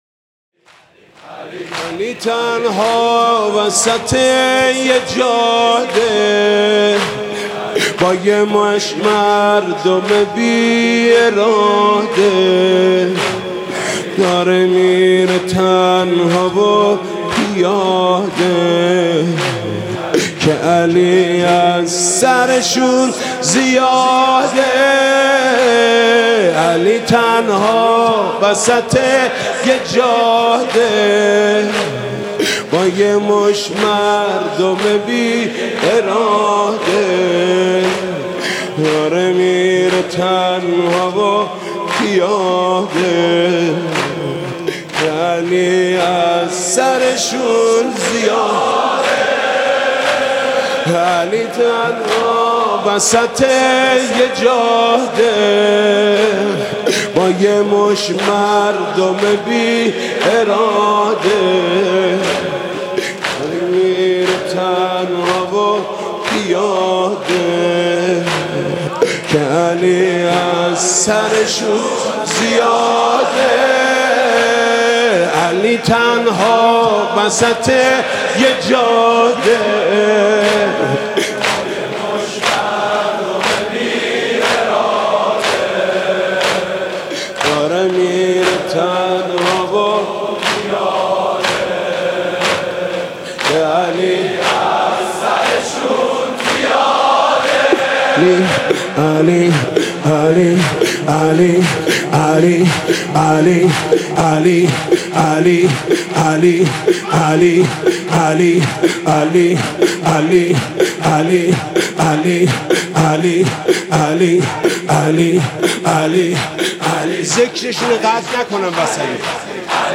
ویژه شب نوزدهم رمضان